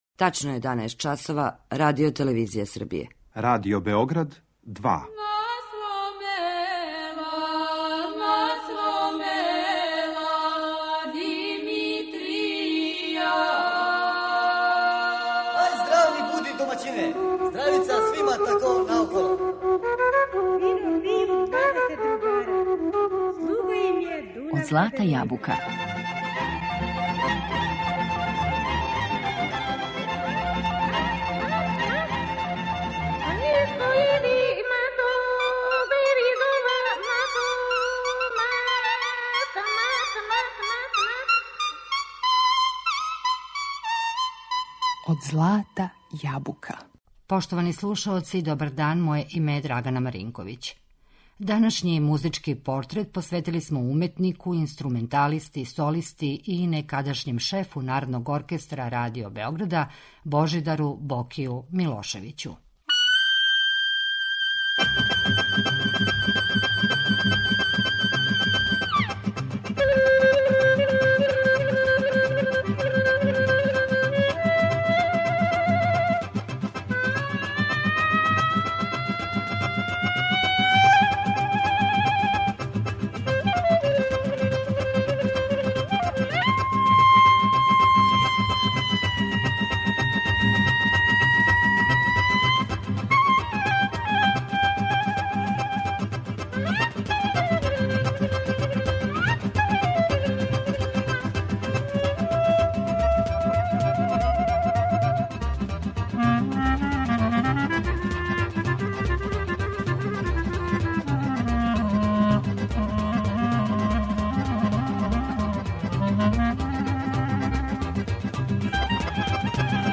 Чаробњак на кларинету, бујним темпераментом, исконском музикалношћу, великом маштом, емотивном и сугестивном интерпретацијом, пренео је славу нашег изворног и стилизованог мелоса широм света.